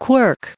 added pronounciation and merriam webster audio
2027_quirk.ogg